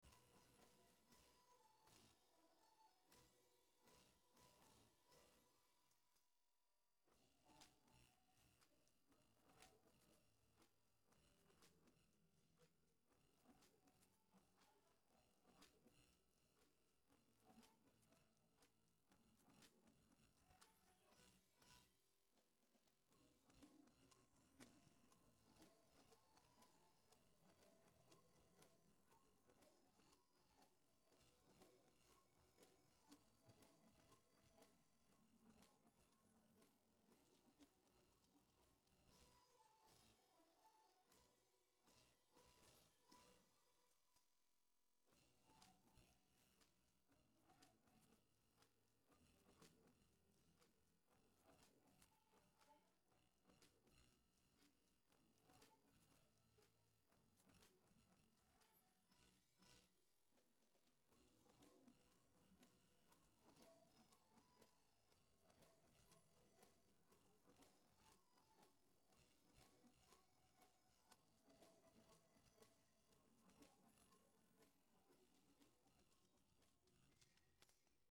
Vokaldel